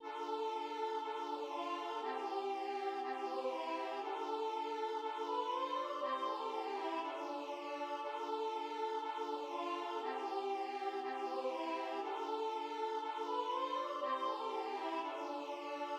accordion
} } \relative c'' { \time 2/4 \key c \major \tempo 4=120 \set Staff.midiInstrument="choir aahs" \repeat volta 2 { a4 a8. a16